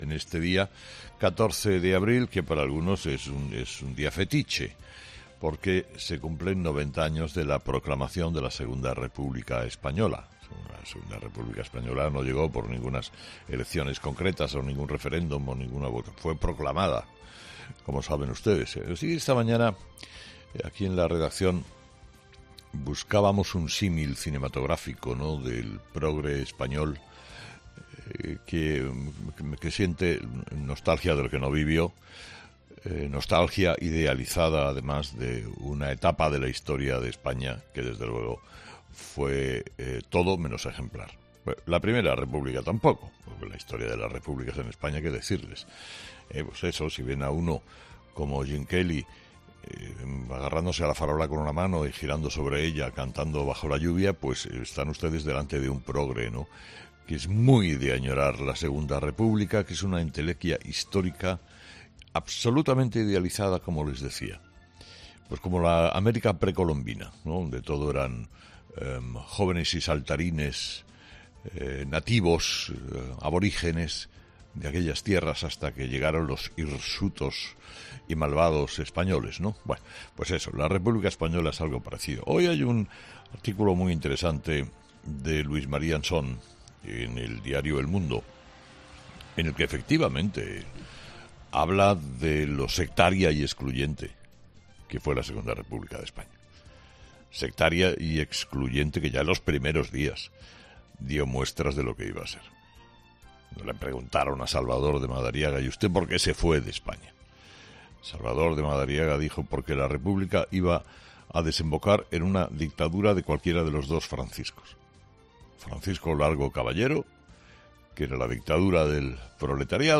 Carlos Herrera, director y presentador de 'Herrera en COPE' ha comenzado el programa de este miércoles analizando las principales claves de la jornada, que pasan por la actualidad que nos deja las últimas informaciones relacionadas con el plan de vacunación, y que pasan por el parón en el envío de las vacunas de Janssen.